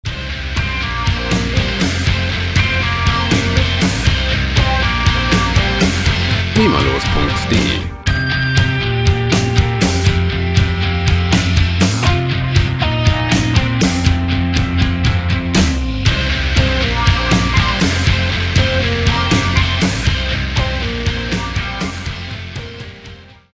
• Pop-Rock